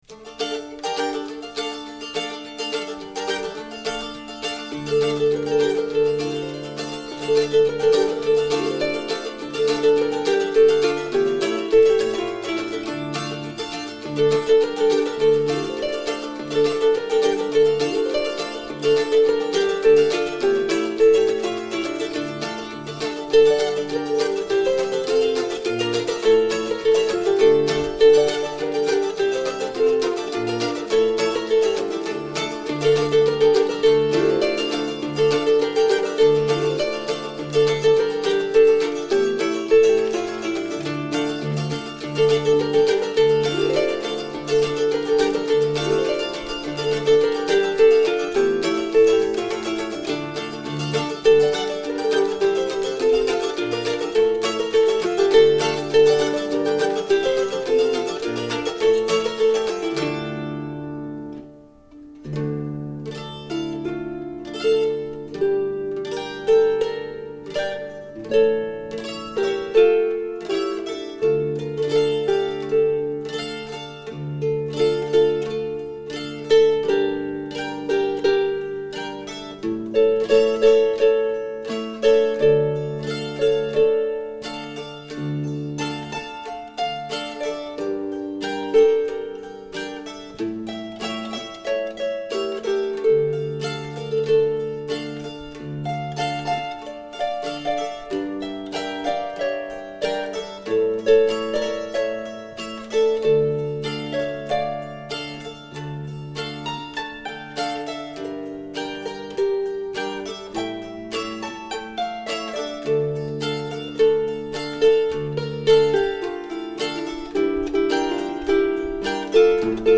EN CONCERT A L' ABBAYE DE CAUNES MINERVOIS LE 24 FEV.2006
HARPE CELTIQUE